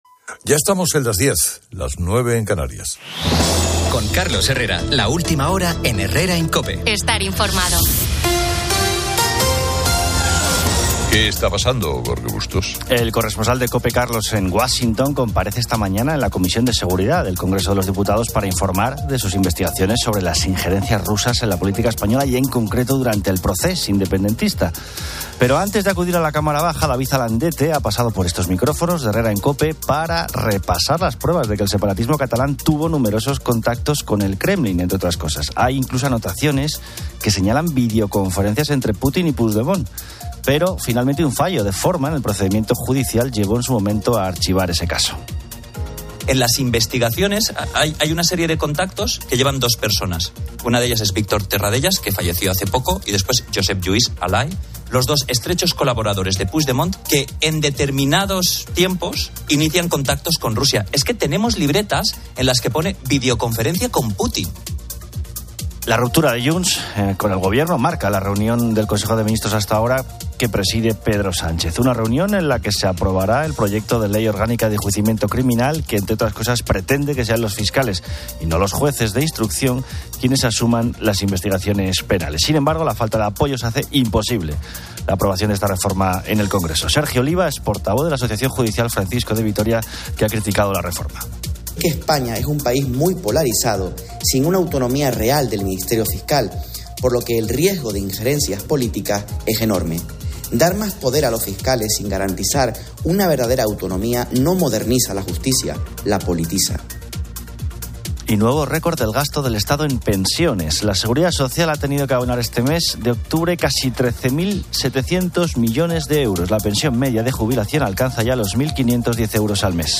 Los oyentes comparten experiencias surrealistas, como el encuentro con Miguel Ríos, la aparición en un videoclip de Fitipaldis, o la recuperación inesperada de dinero.